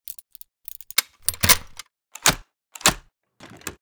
Plastic_Small_Pick.ogg